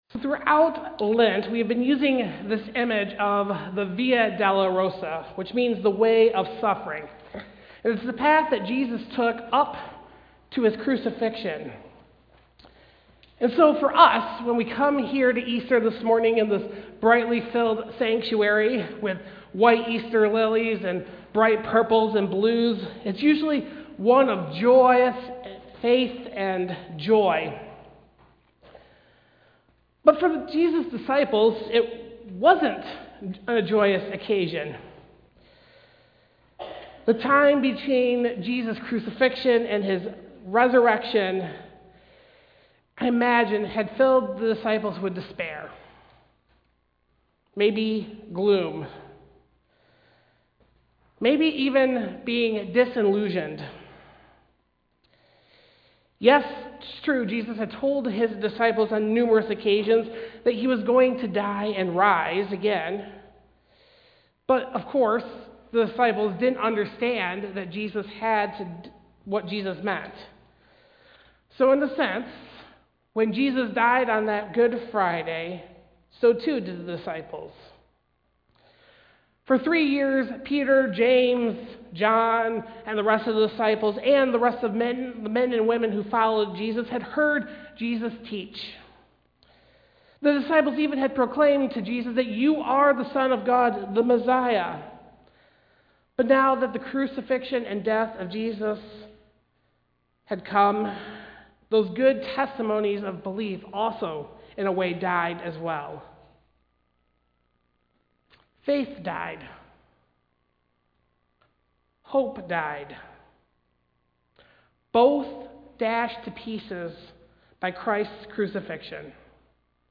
Passage: John 20:10-18 Service Type: Holiday Service « Palm Sunday